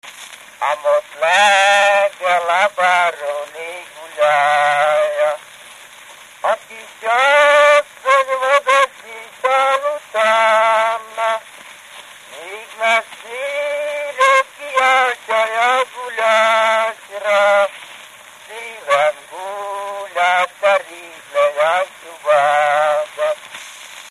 Felföld - Hont vm. - Lukanénye
Stílus: 8. Újszerű kisambitusú dallamok
Szótagszám: 6.6.6.6
Kadencia: 1 (3) 3 1